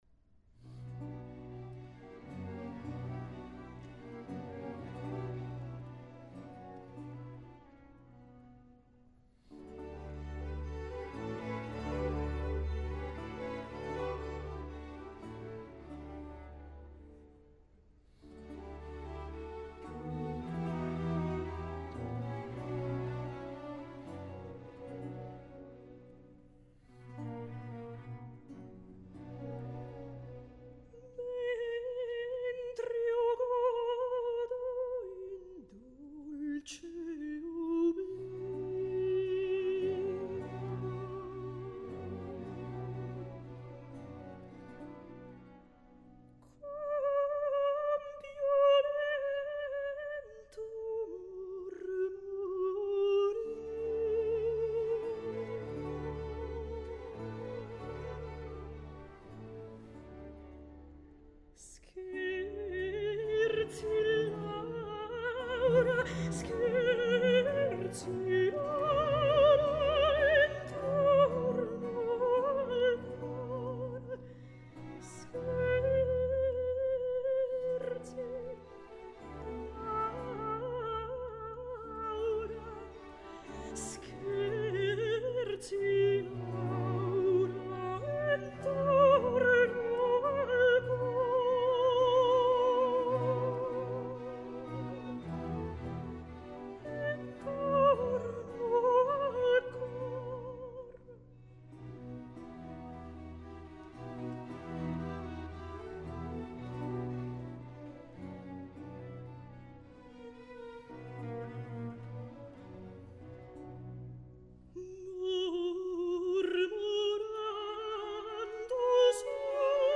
ària